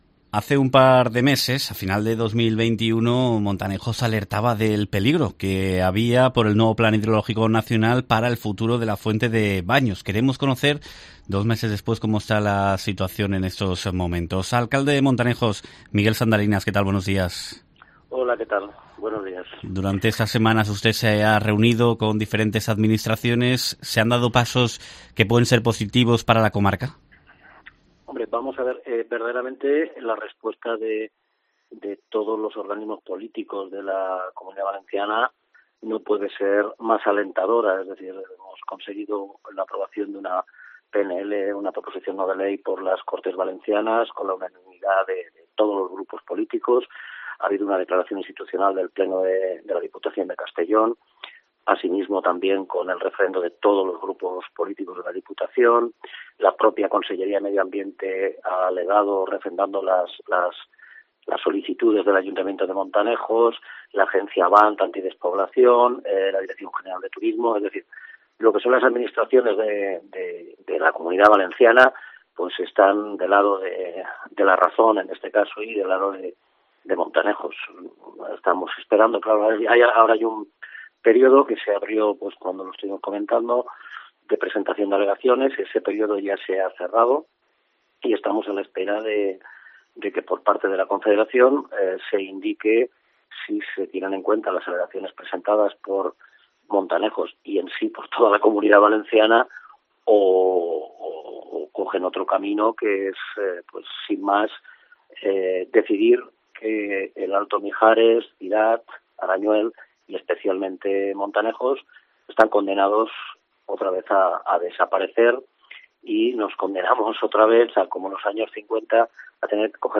El alcalde, Miguel Sandalinas, señala a COPE que, según estudios realizados por la Universidad Politécnica de Valencia y la Universitat Jaume I, serían más de 500 los empleos que podrían perderse, algo que condenaría a la desaparición no solo de Montanejos, también de otros municipios como Cirat o Arañuel.